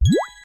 Total_Settle_Collect_Click.mp3